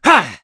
Oddy-Vox_04.wav